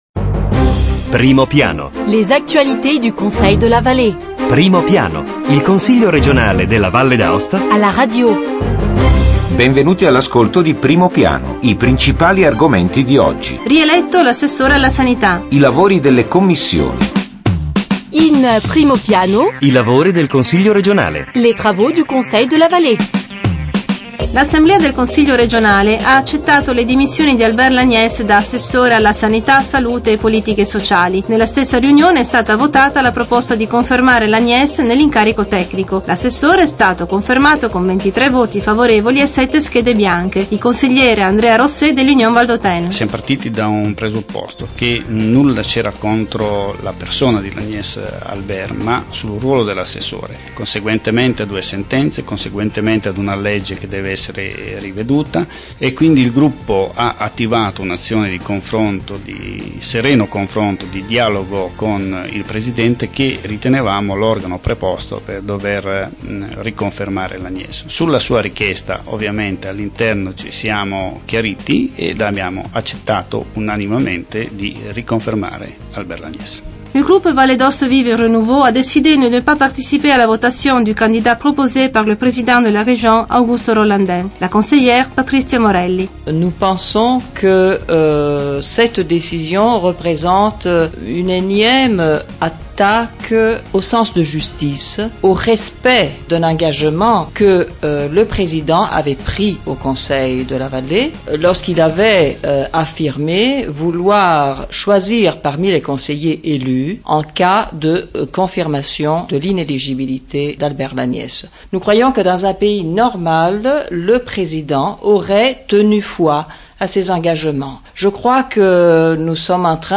Eventi e ricorrenze Documenti allegati 29 settembre 2009 Primo piano Il Consiglio regionale alla radio: approfondimento settimanale sull'attivit� politica, istituzionale e culturale dell'Assemblea legislativa. Questi gli argomenti del nuovo appuntamento con Primo Piano: i lavori del Consiglio Valle, in particolare la rielezione dell�Assessore alla sanit�, salute e politiche sociali, con le interviste ai Consiglieri Andrea Rosset (UV) e Patrizia Morelli (VdA Vive/Renouveau); le riunioni delle Commissioni consiliari permanenti. Scopriremo poi gli appuntamenti e le iniziative istituzionali previsti per la settimana.